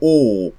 Dutch flemishguy (Low Quality)
A single-speaker model for Dutch based on the flemishguy dataset.
23 o vowel close-mid back rounded [
close-mid_back_rounded_vowel.wav